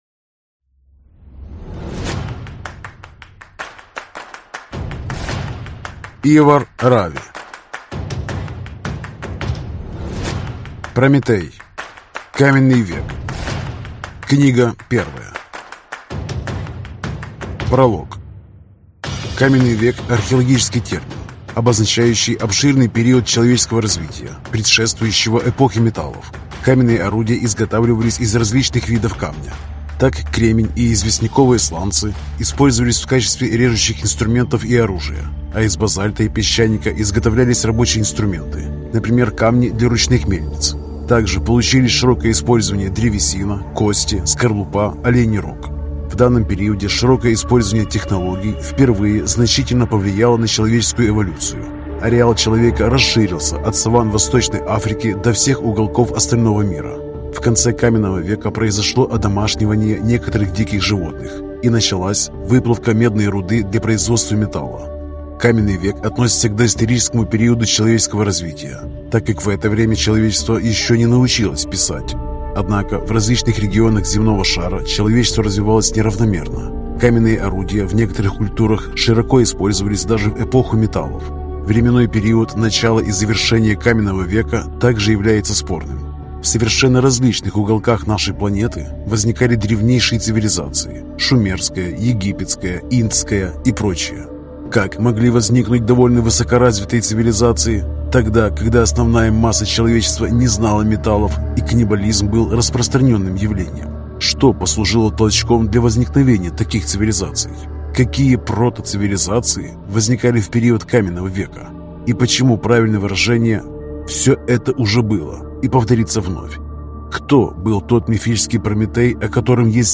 Аудиокнига Каменный век | Библиотека аудиокниг